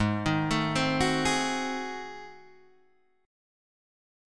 G#7b9 chord